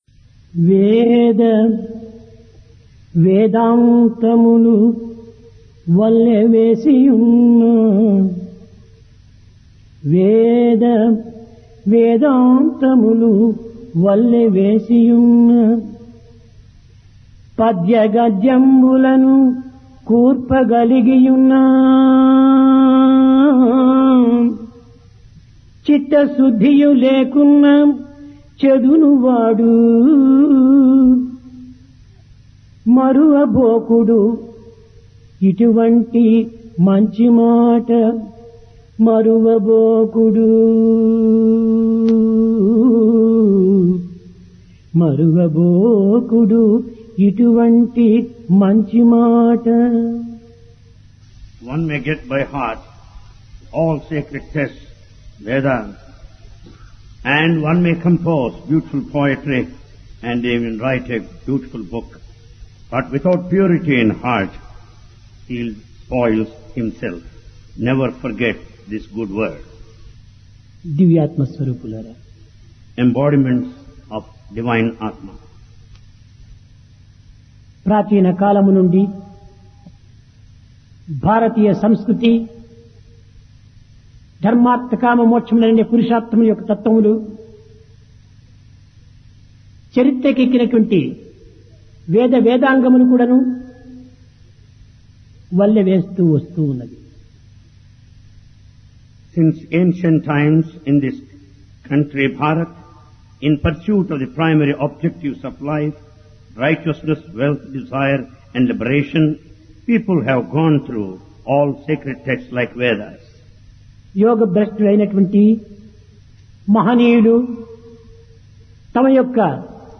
Divine Discourse to University Teachers and Students | Sri Sathya Sai Speaks
Place Prasanthi Nilayam